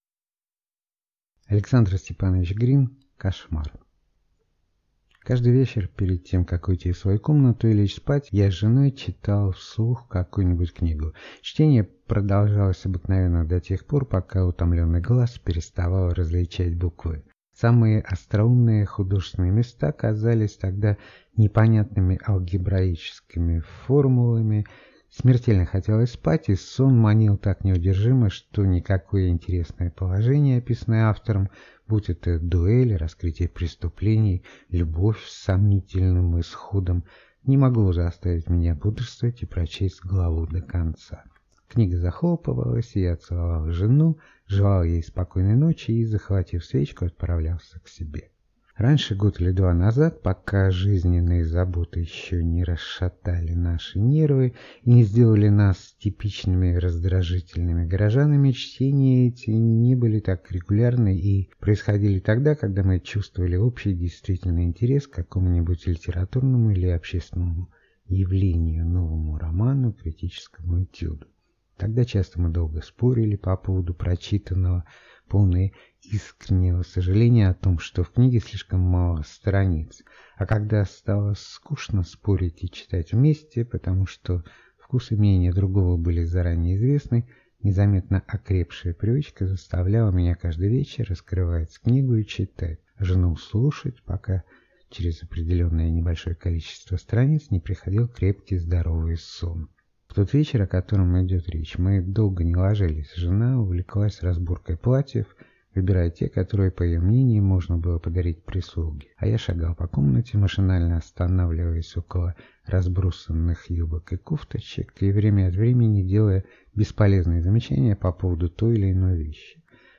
Аудиокнига Кошмар | Библиотека аудиокниг